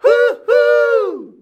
HUHUUUH D.wav